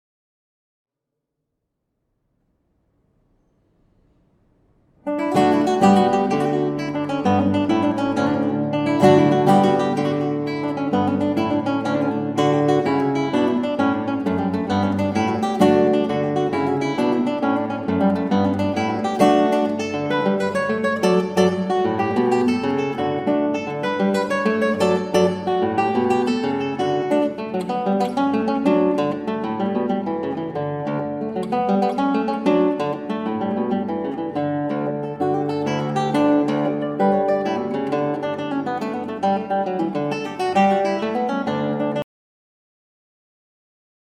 Gitaar
Met mijn zacht getokkel verover ik vele harten van romantische zieltjes.